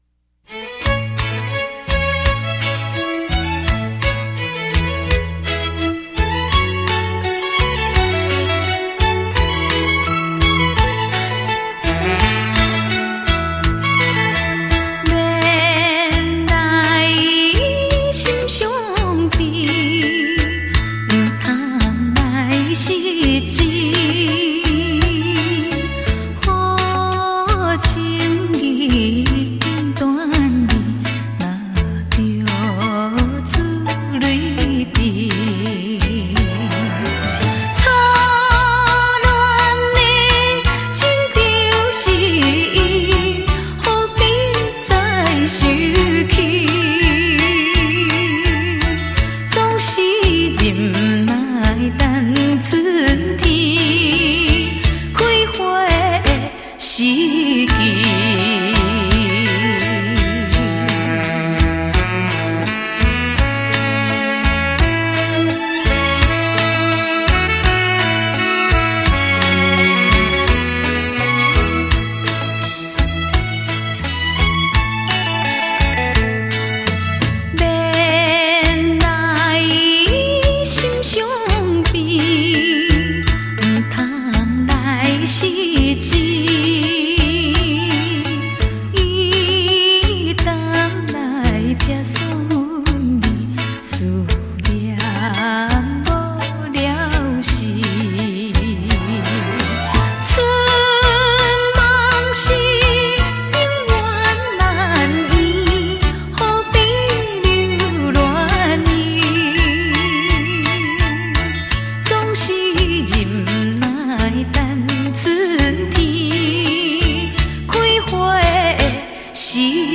歌唱版
立体演唱会 环绕身历声